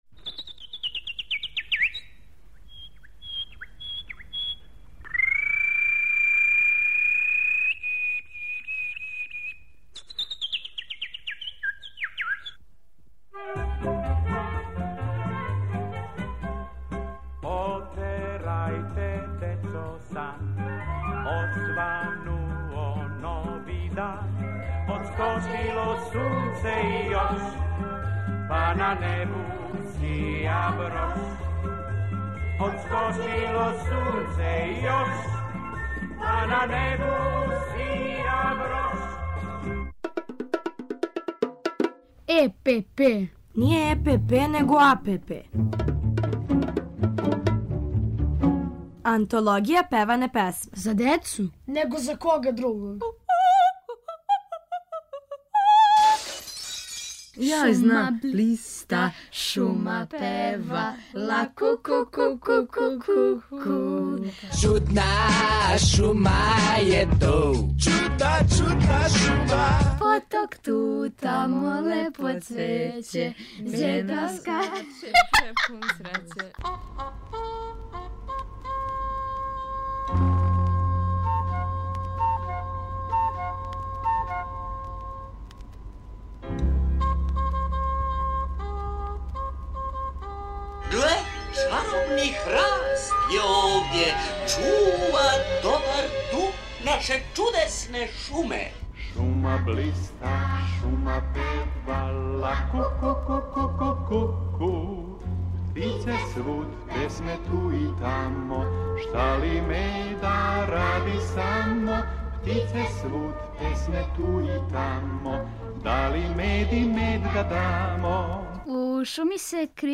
У серијалу Антологија певане песме, Колибри пева о шуми а Дечија драмска група размишља како да се одбрани од медведа!